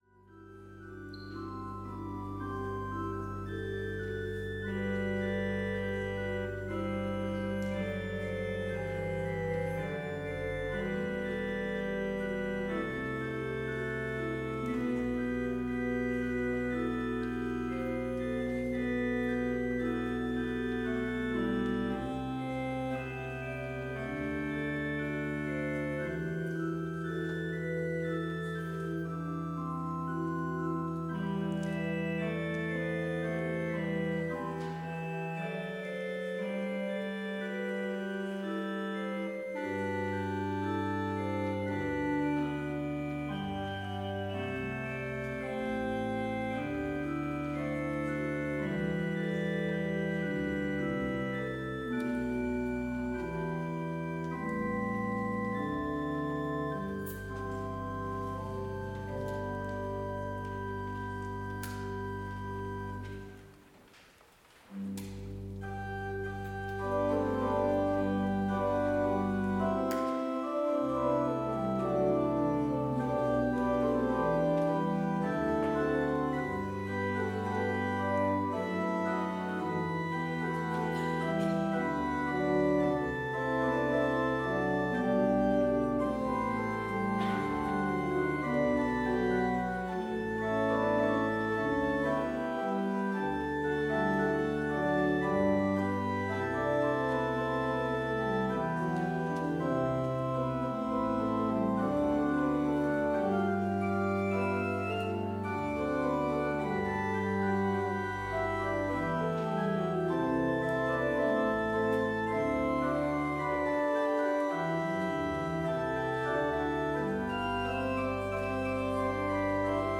Vespers service in Bethany Lutheran College's chapel
choral setting
Complete service audio for Vespers - Wednesday, November 6, 2024